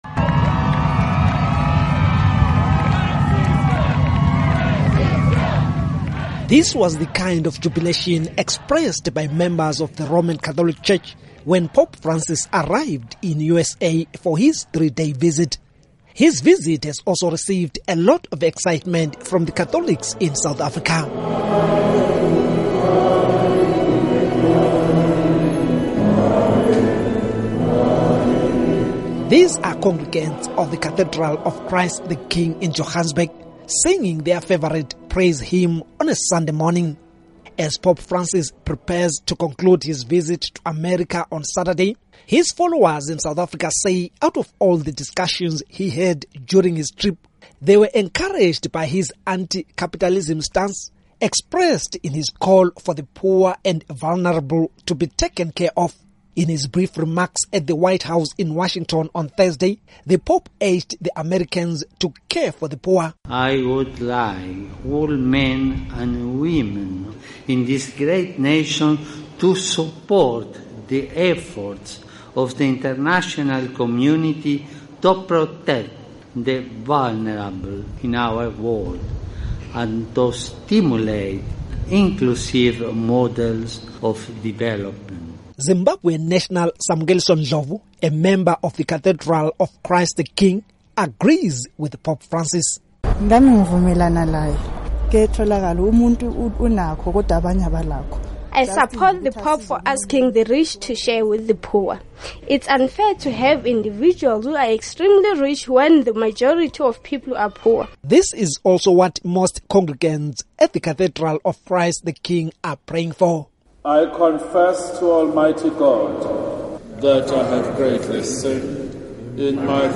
Report on Pope Francis Visit